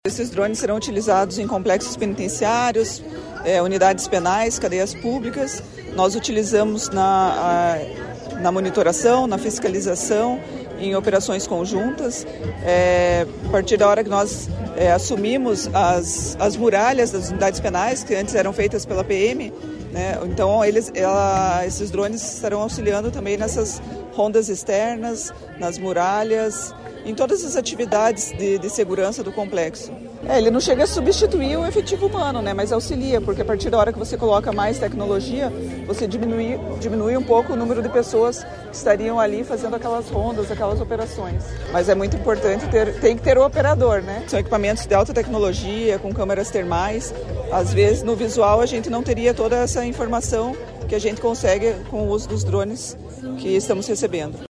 Sonora da diretora-geral da Polícia Penal, Ananda Chalegre, sobre a entrega de 243 drones para monitoramento aéreo das forças de segurança do Paraná